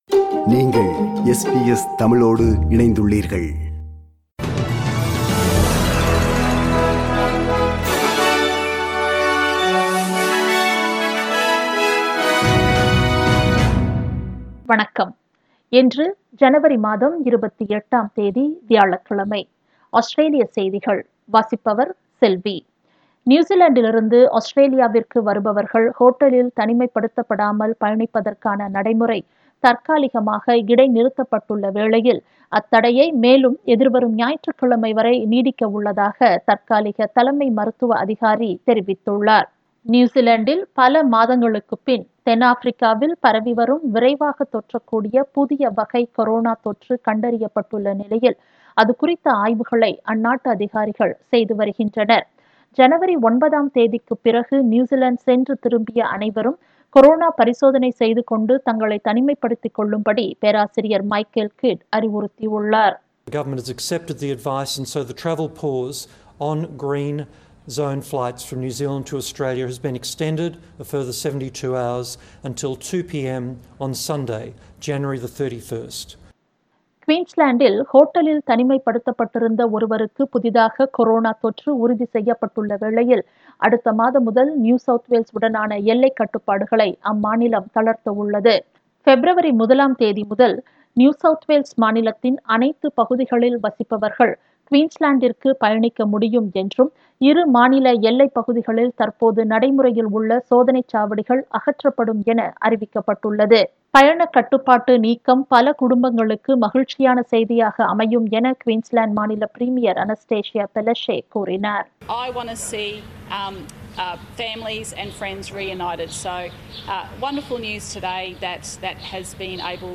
Australian news bulletin for Thursday 28 January 2021.